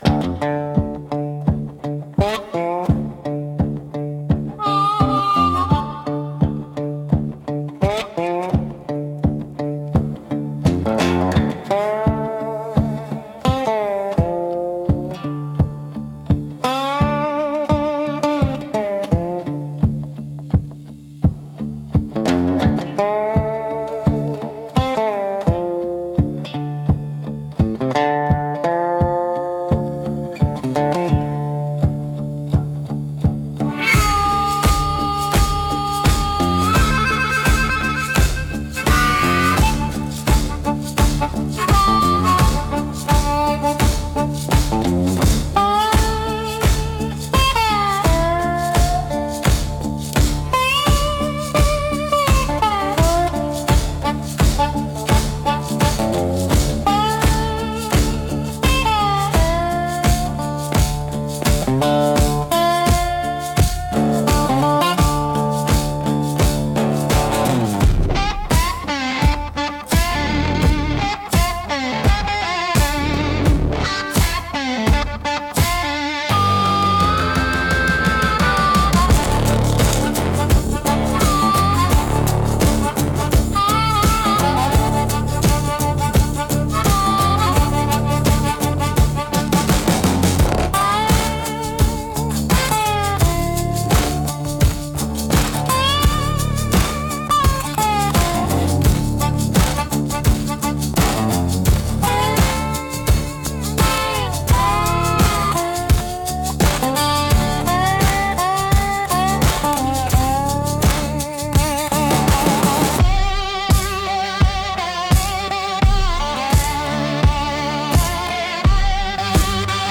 Instrumental - Suspended in the Drop 3.35